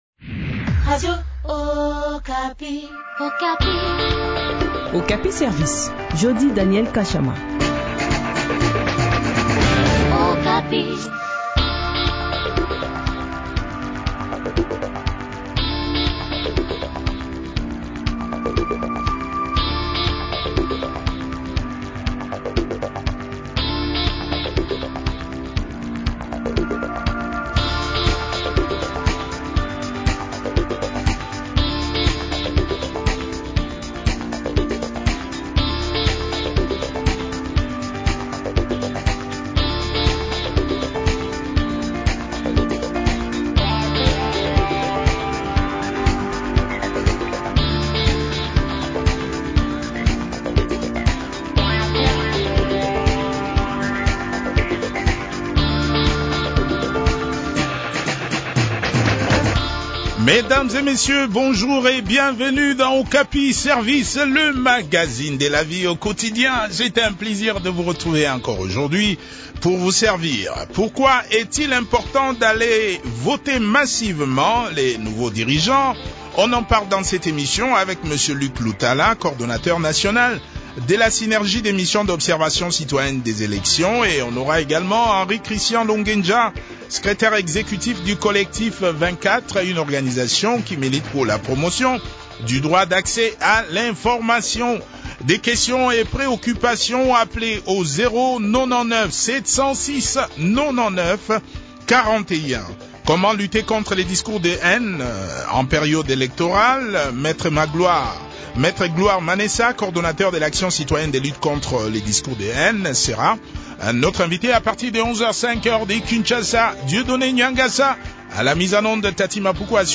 a également pris part à cet entretien.